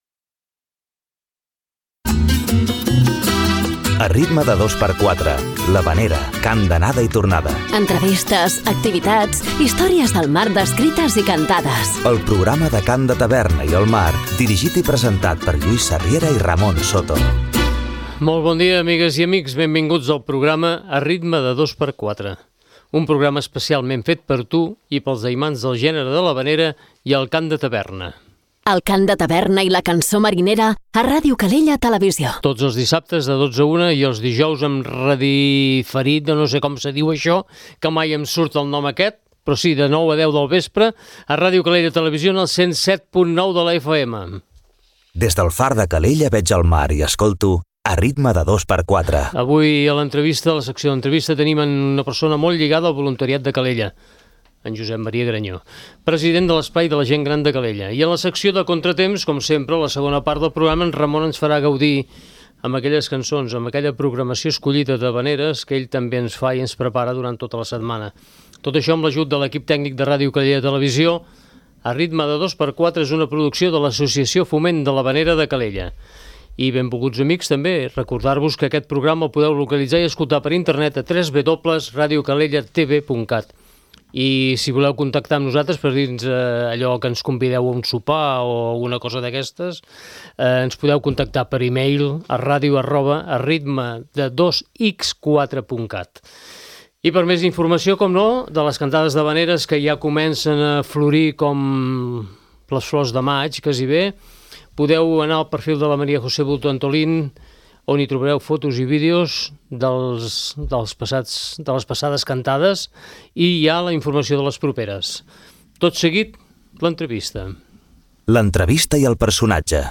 Avui a l'entrevista parlem amb la gent de l'Espai Caixa, que demà organitzen un espectacle solidari per recaptar fons per la Marató de TV3.